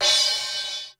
Index of /90_sSampleCDs/300 Drum Machines/Korg DSS-1/Drums01/06
Crash.wav